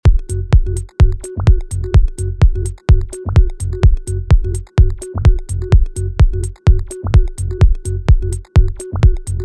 Звук пробки